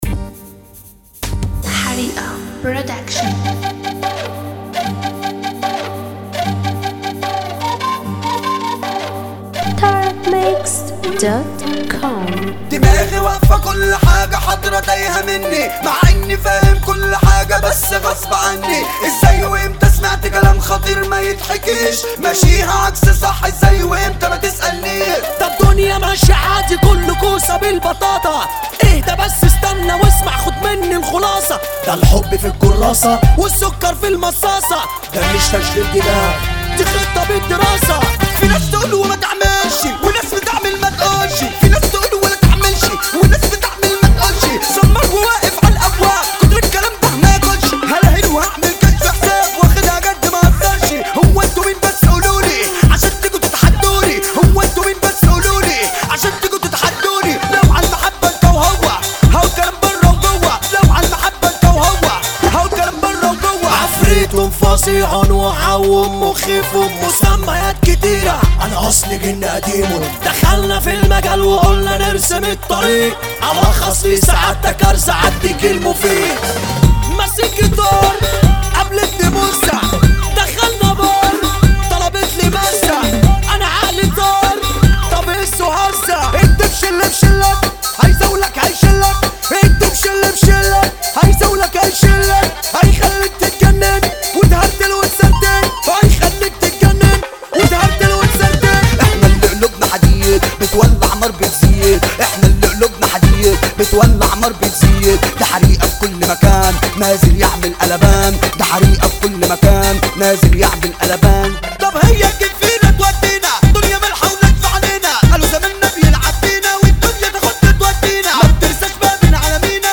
• النوع : shobeiat